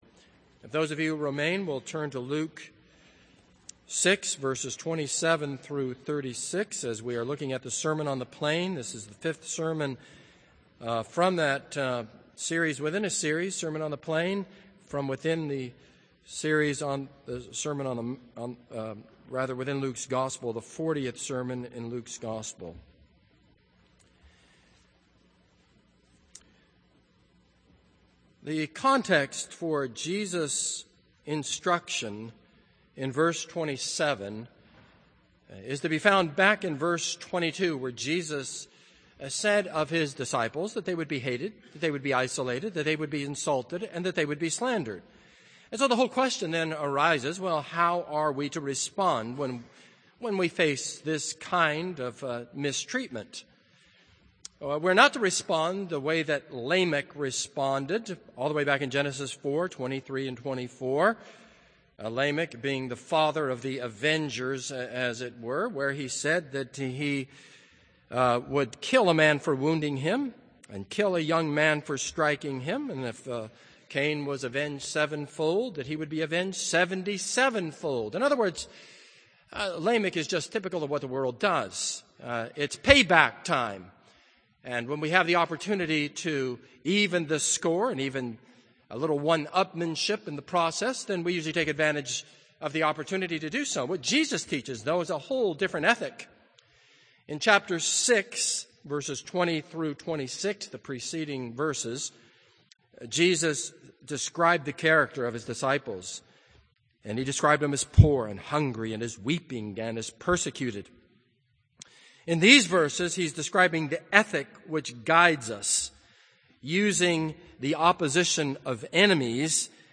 This is a sermon on Luke 6:27-35.